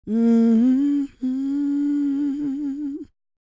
E-CROON P311.wav